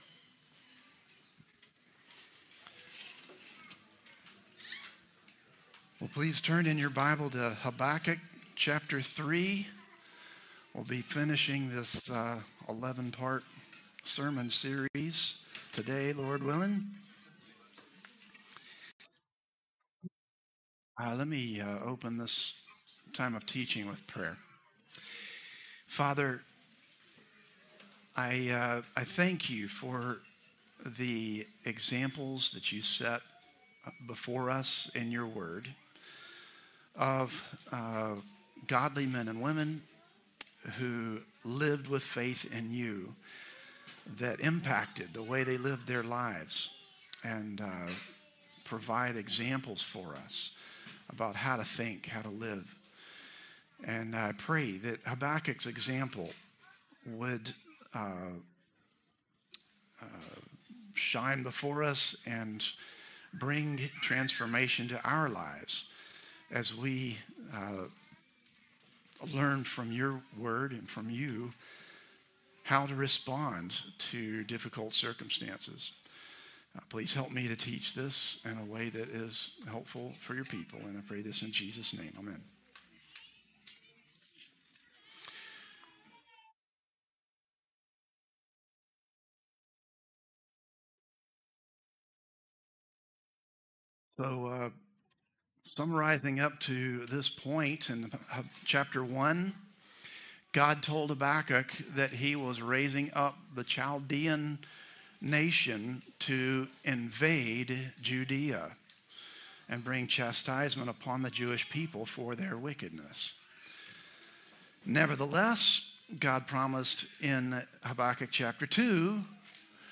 Christ the Redeemer Church | Sermon Categories Prayer